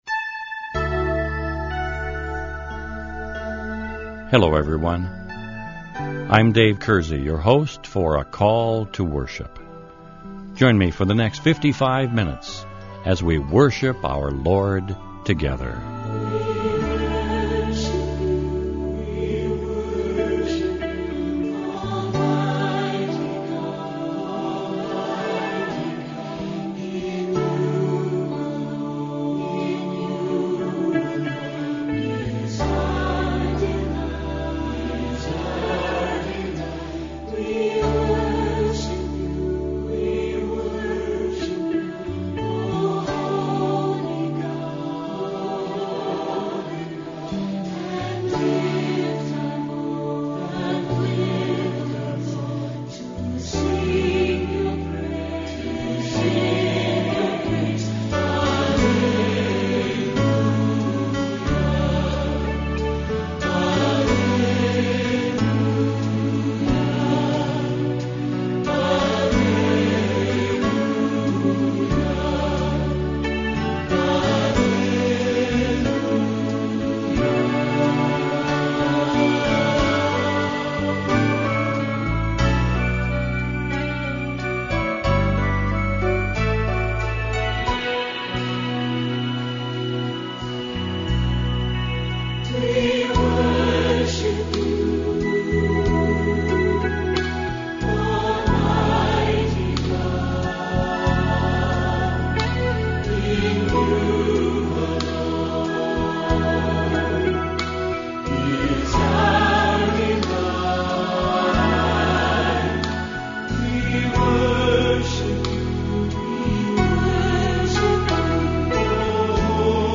On this week’s Call To Worship program we are going singing some songs and sharing some thoughts from Dr. Charles Stanley about the importance of having a personal relationship and fellowship with our Savior and Lord, Jesus, the Christ. Activity, thought essential to practical faith, is not a substitute for personal fellowship.